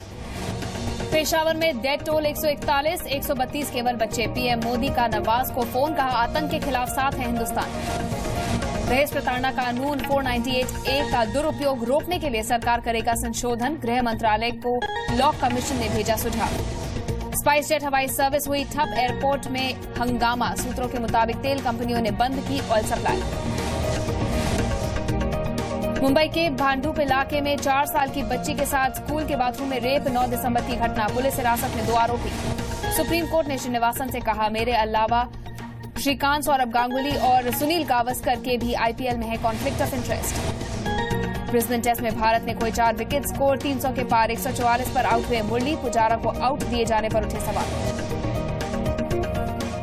Top headlines of the day